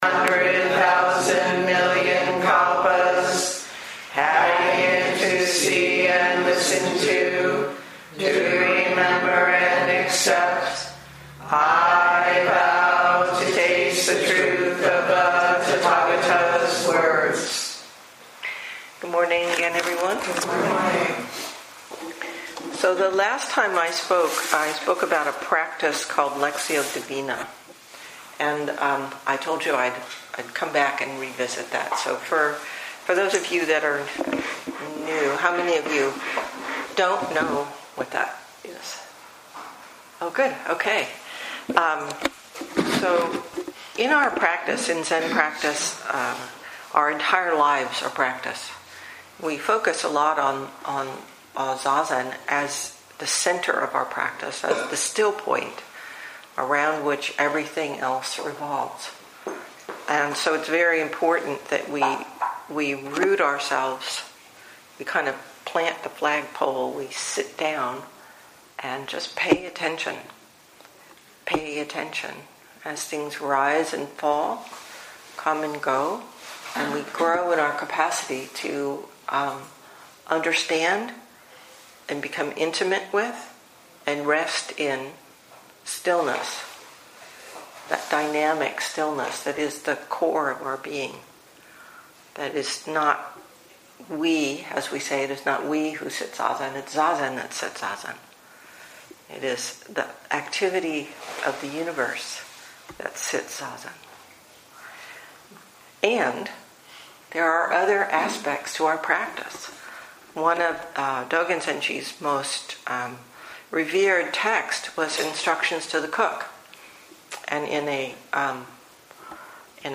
2018 in Dharma Talks